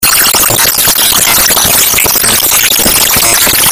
саундтрек из рекламы